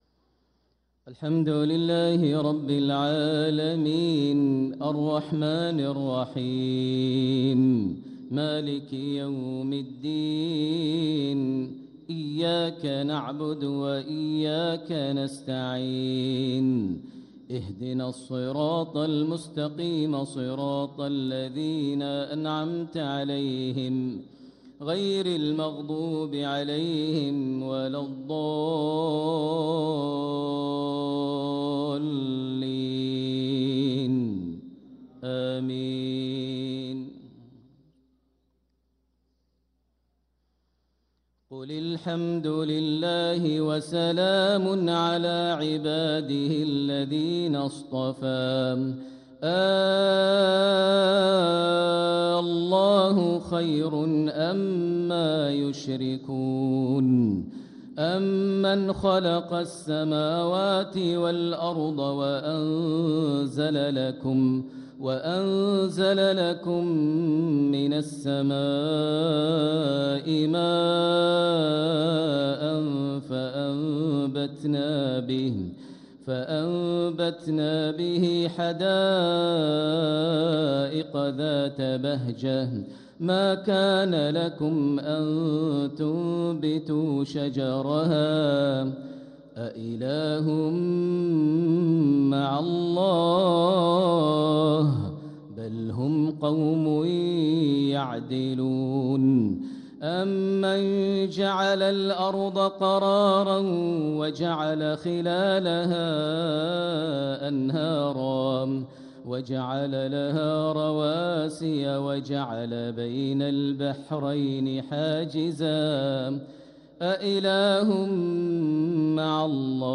صلاة المغرب للقارئ ماهر المعيقلي 17 صفر 1446 هـ
تِلَاوَات الْحَرَمَيْن .